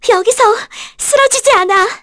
Frey-Vox_Skill4_kr.wav